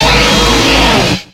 Cri de Rhinastoc dans Pokémon X et Y.